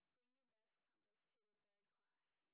sp27_street_snr30.wav